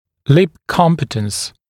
[lɪp ‘kɔmpɪtəns][лип ‘компитэнс]смыкаемость губ, т.е. контакт губ без мышечного напряжения